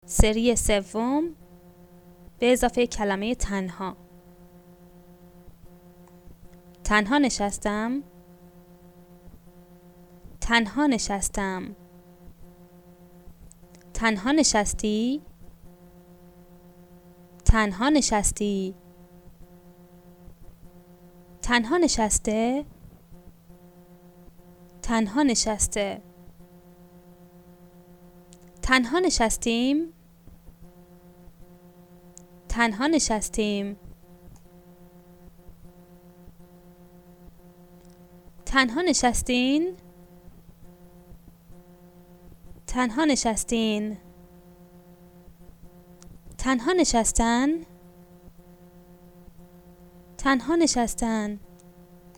The Present Perfect is stressed on the last syllable and the Simple Past on the penultimate syllable.